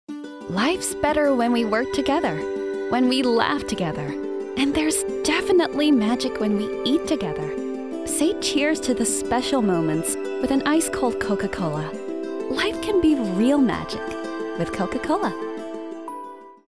commercial
friendly
warm